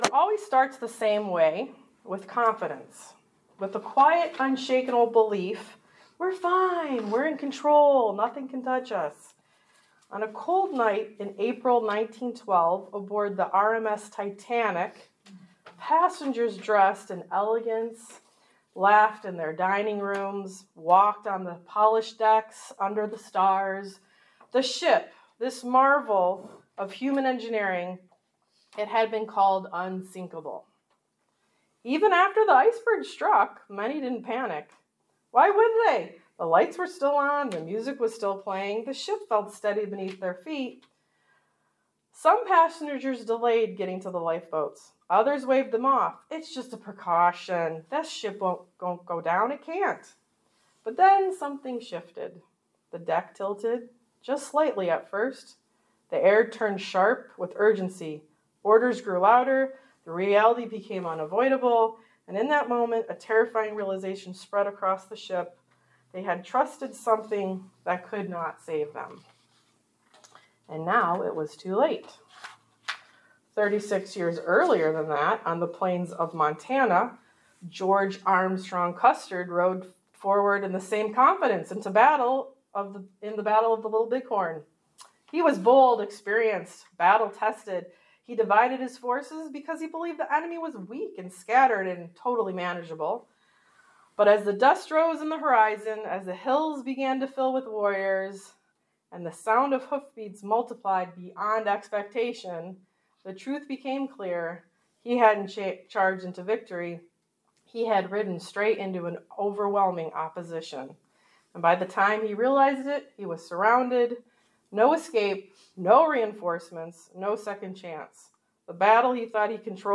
Service Type: Women's Bible Study